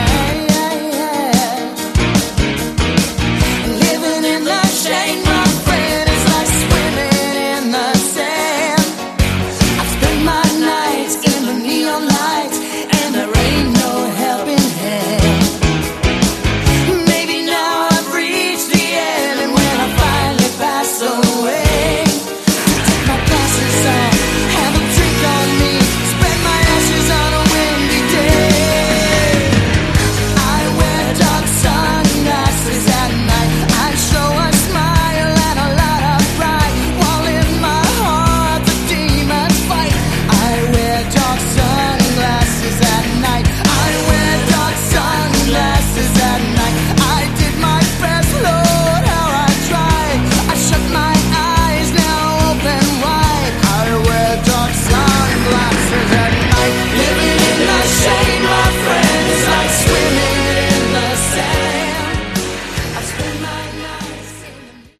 Category: Melodic Power Metal
vocals
guitars
keyboards
bass
drums